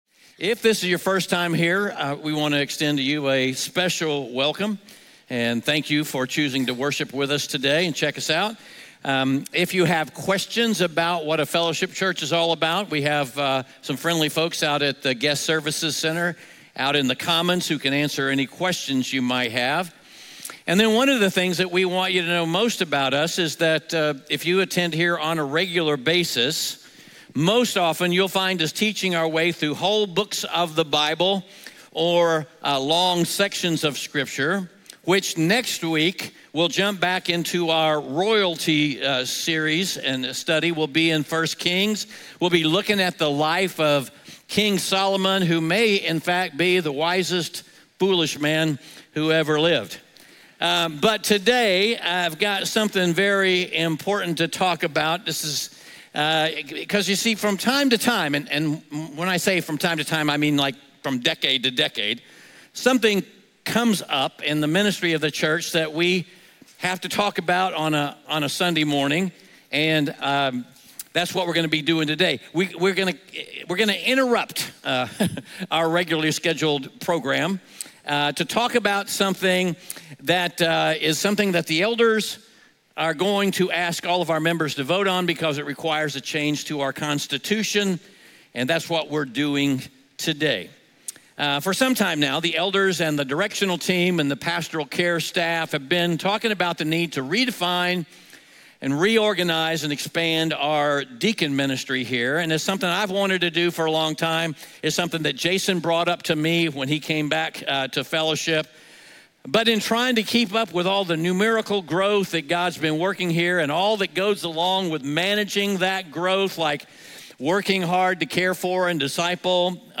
Audio messages from Fellowship Greenville in Greenville, SC